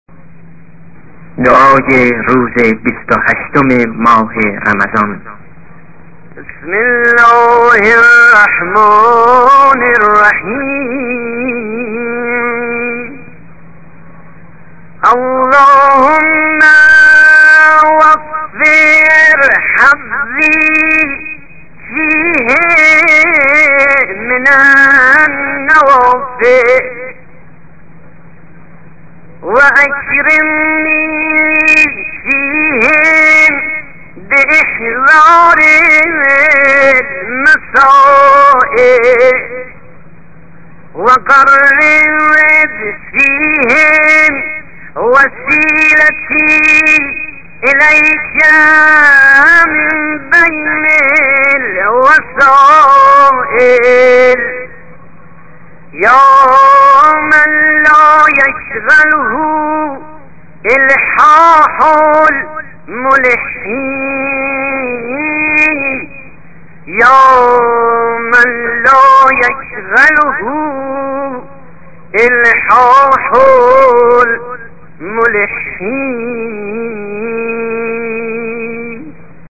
ادعية أيام شهر رمضان